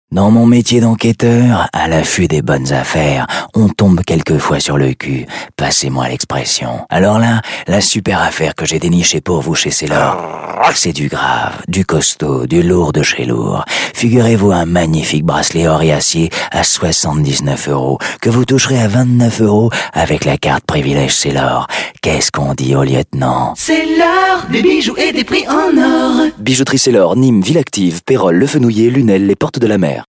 Voix d'homme en français ⋆ Domino Studio
PUB